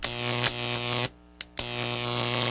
Electricity.WAV